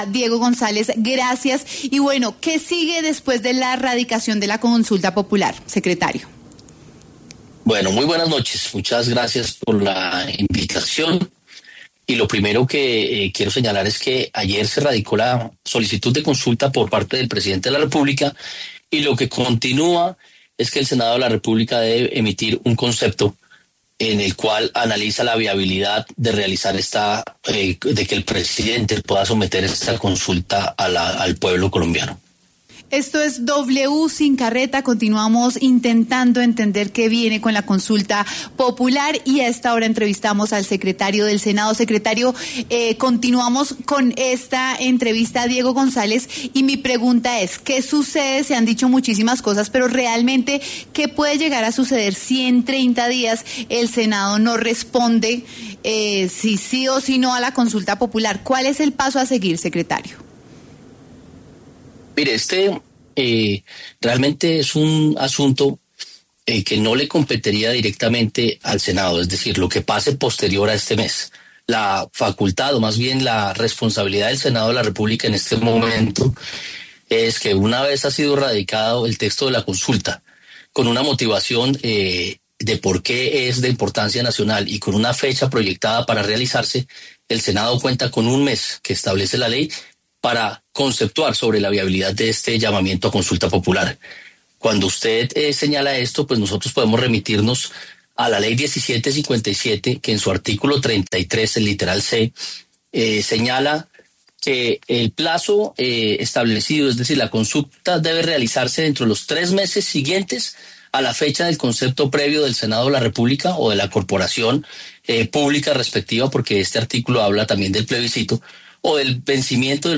A propósito de esto, W Sin Carreta conversó con Diego González, secretario del Senado de la República, para conocer que viene en este proceso.